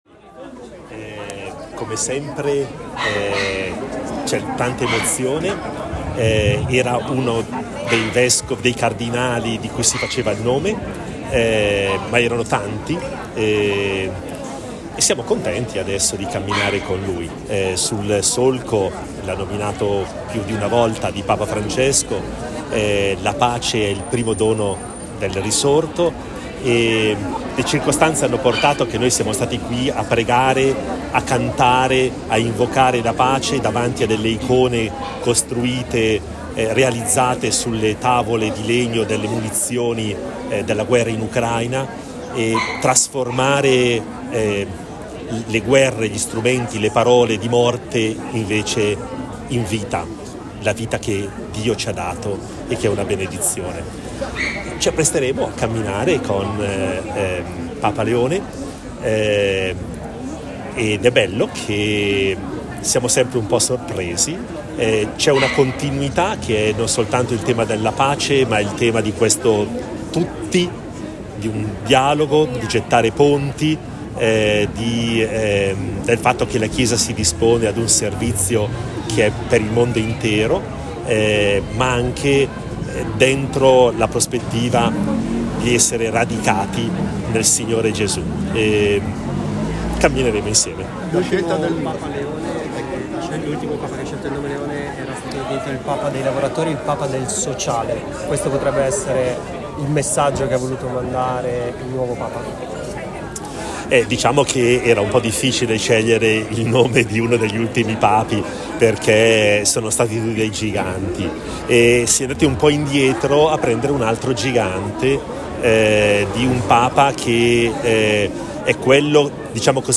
Pubblichiamo il video del momento in cui il Vescovo ascolta in diretta web l’annuncio dalla Loggia delle Benedizioni e l’audio con le prime dichiarazioni rilasciate alla stampa.